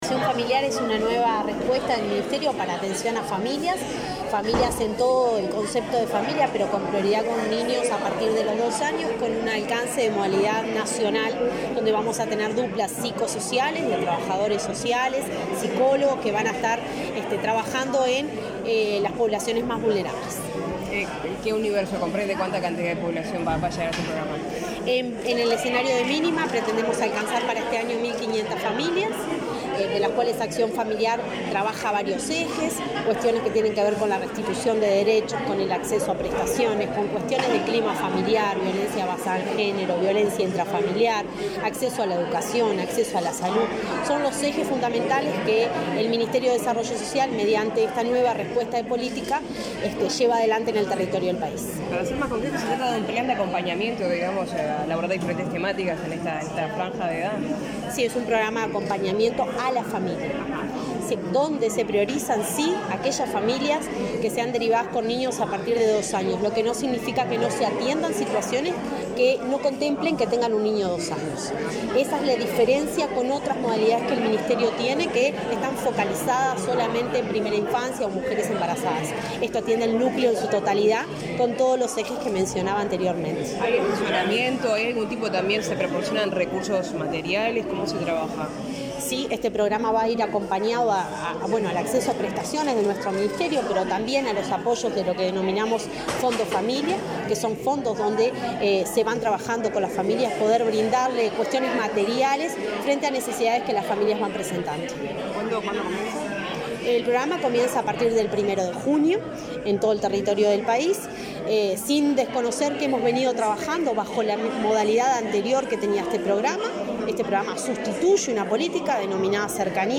Declaraciones a la prensa de la directora de Desarrollo Social, Cecilia Sena
La directora nacional de Desarrollo Social, Cecilia Sena, dialogó con la prensa sobre la importancia de esta iniciativa.